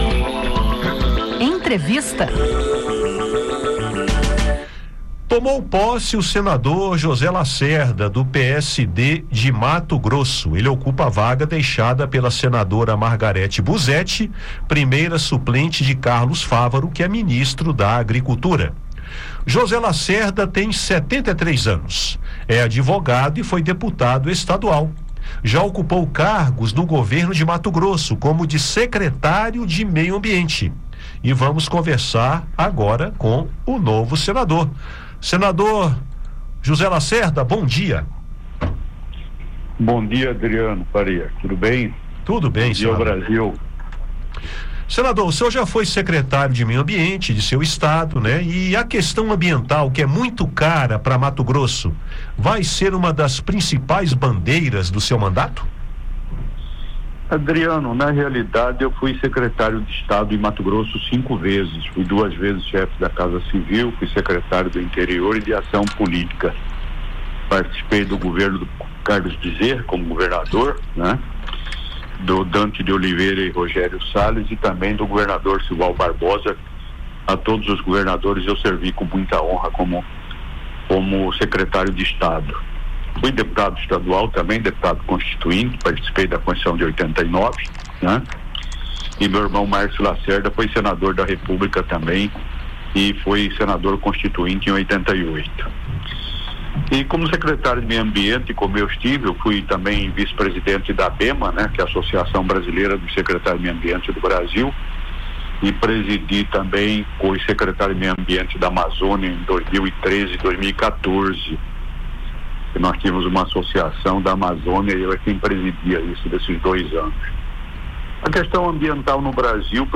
Em entrevista ao Conexão Senado, da Rádio Senado, Lacerda falou sobre as linhas de atuação de seu mandato, com foco na agropecuária e em questões ambientais.